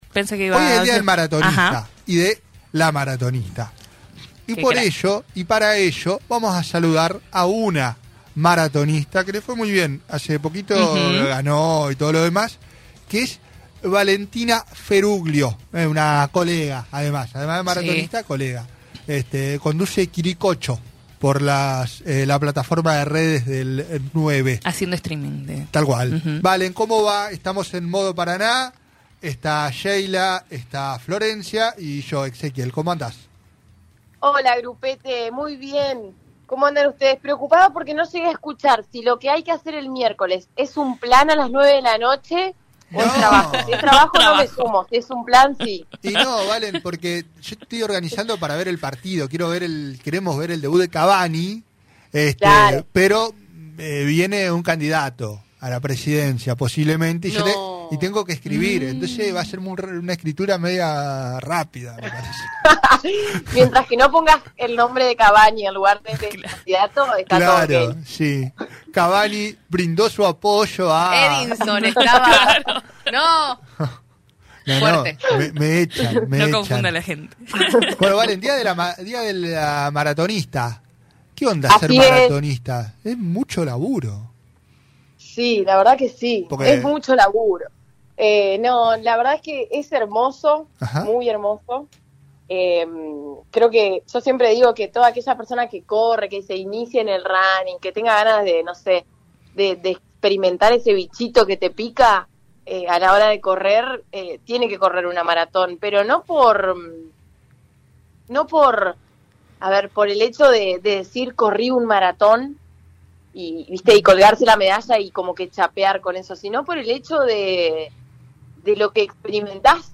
en diálogo con radio “Costa Paraná”.